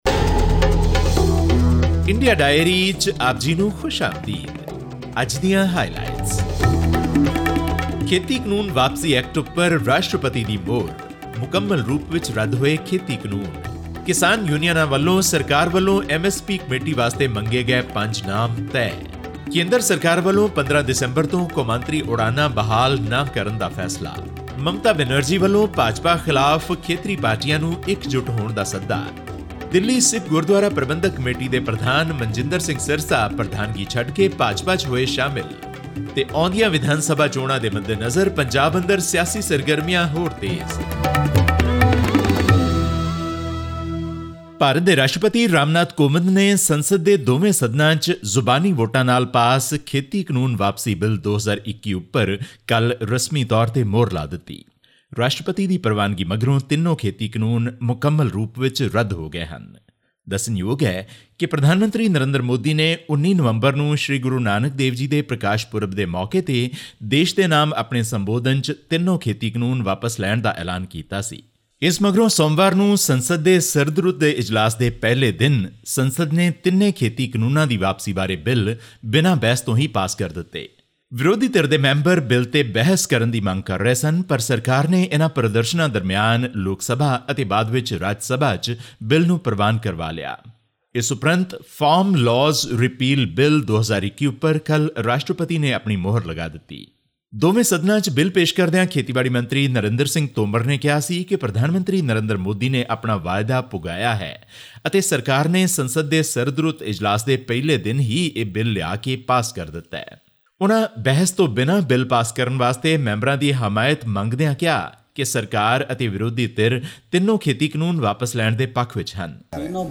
Shiromani Akali Dal (SAD) leader Manjinder Singh Sirsa has joined the Bharatiya Janata Party (BJP), a move perceived by several farm leaders as BJP’s attempt to reach out to Sikh voters ahead of the 2022 assembly elections. All this and more in our weekly news segment from India.